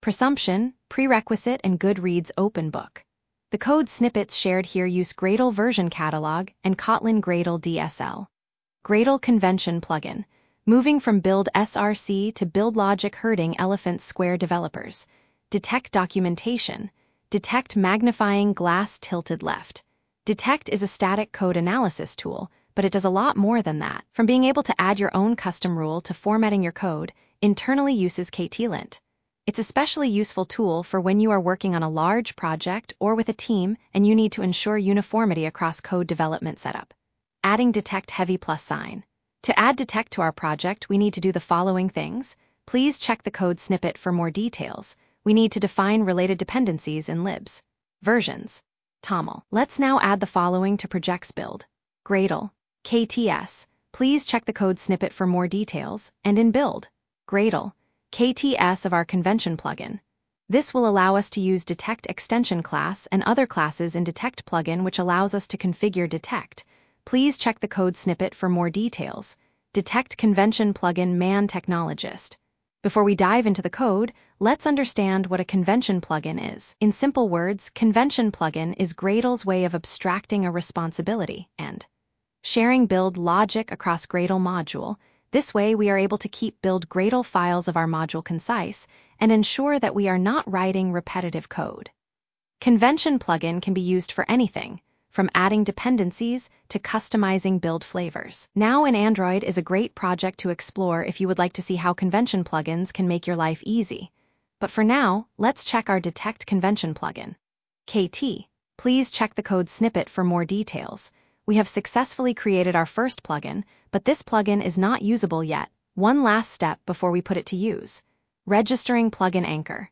AI-generated narration